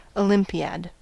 Uttal
Alternativa stavningar olympiad Ol. Uttal US Okänd accent: IPA : /əˈlɪmpiæd/ Förkortningar Ol.